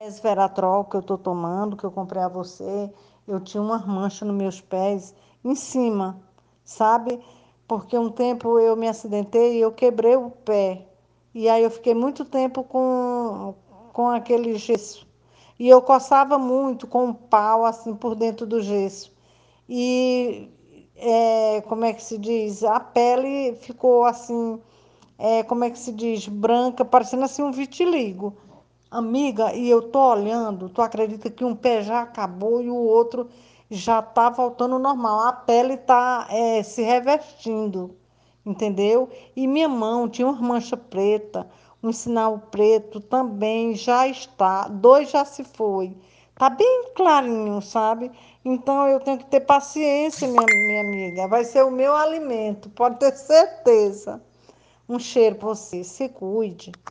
Depoimentos em Aúdio de pessoas que usa o Trans Resveratrol Gota: